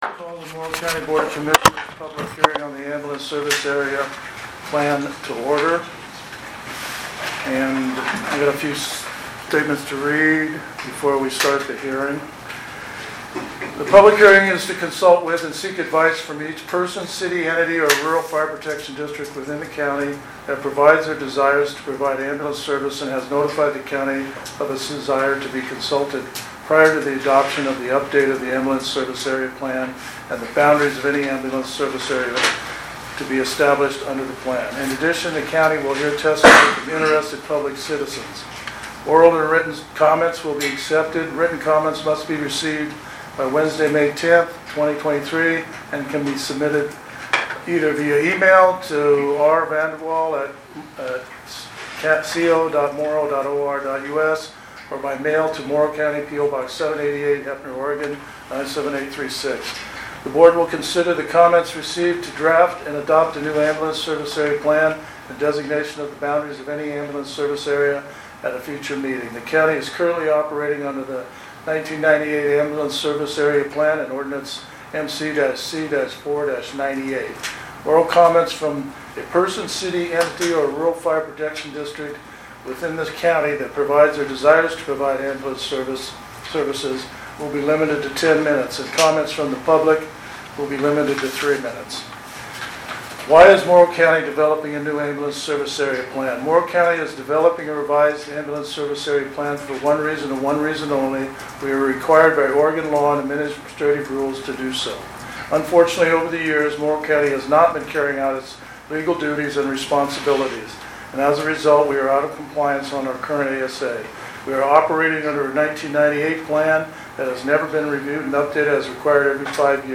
Board of Commissioners Public Hearing - Heppner | Morrow County Oregon
5-3-23_boc_asa_public_hearing_heppner_6_pm.mp3